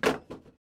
sfx-bump2.ogg